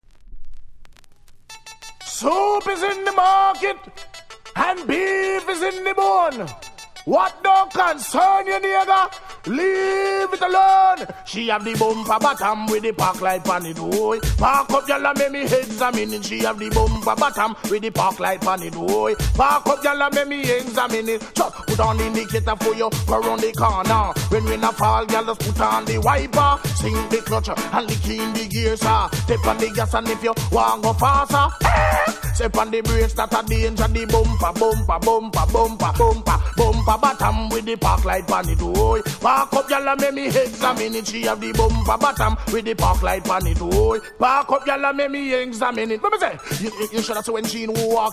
Genre=[ Dancehall Dj ]